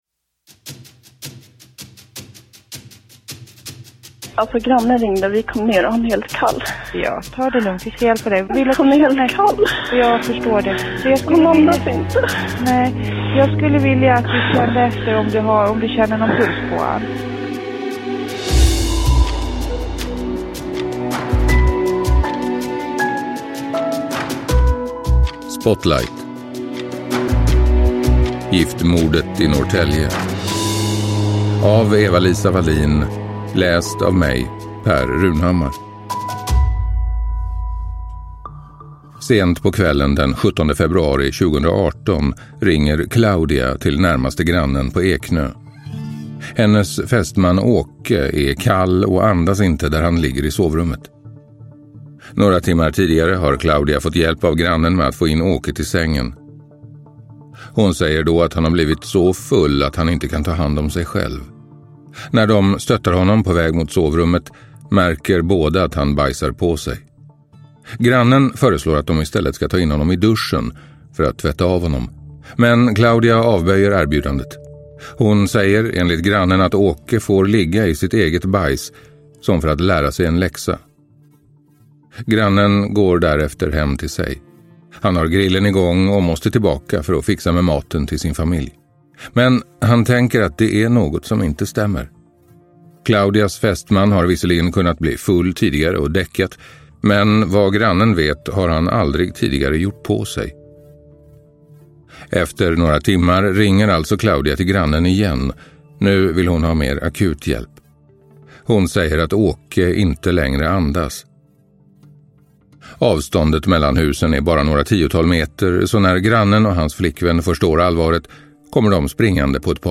Giftmordet i Norrtälje – Ljudbok – Laddas ner